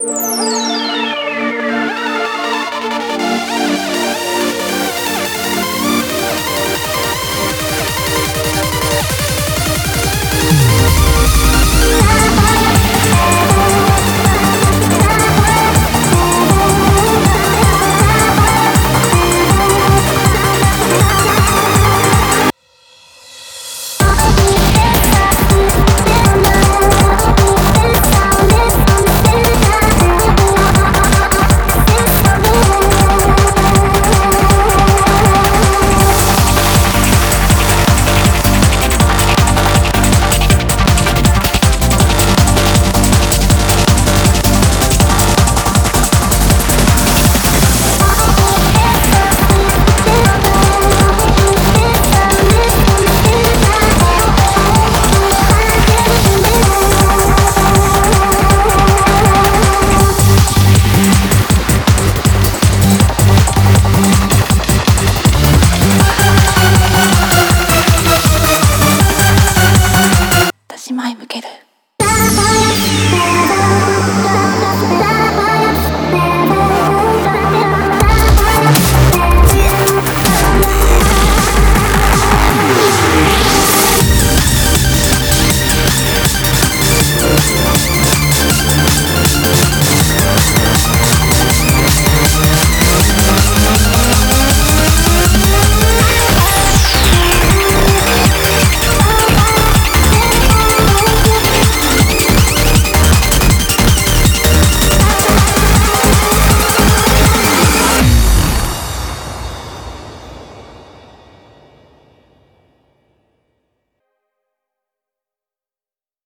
BPM80-160